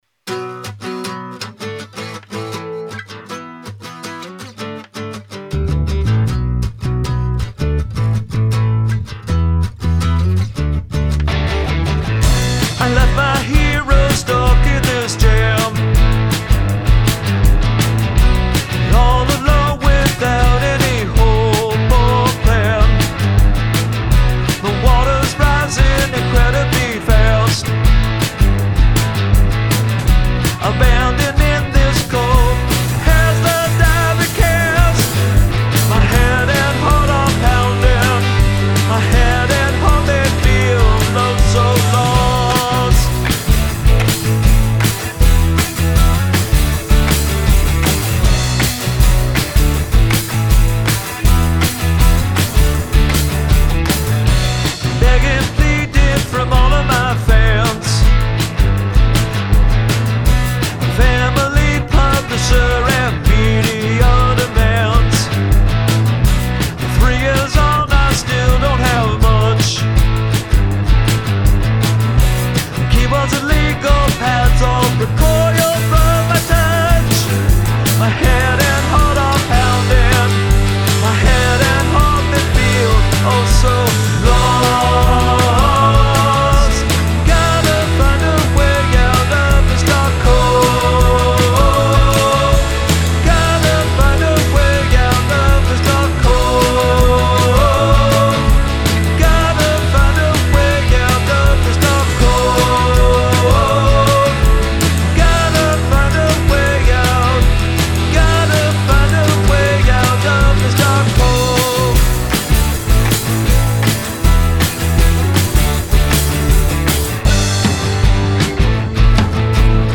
Make use of handclaps and snaps
Bass, Guitar
Piano, Organ
Drums, Guitar, Acoustic Guitar, Vocals
Slide solo absolutely soars!